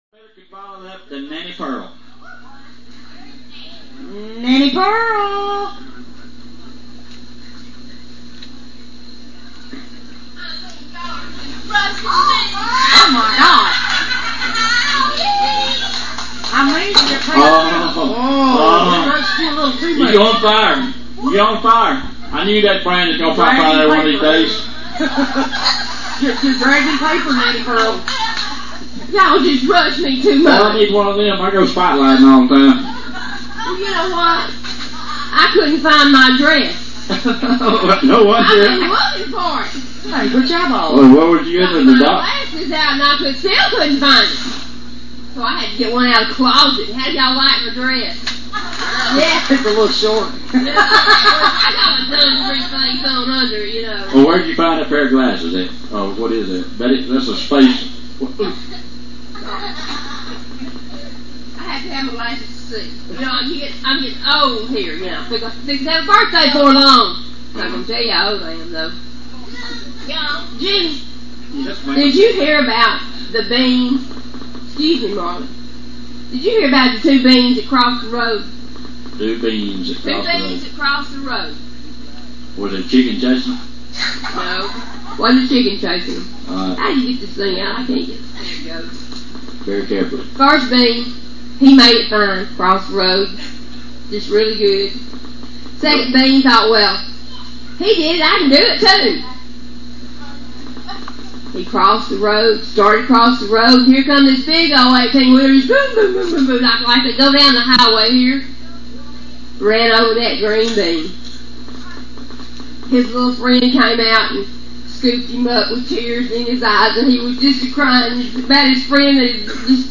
VFD Firehouse Jamboree Story!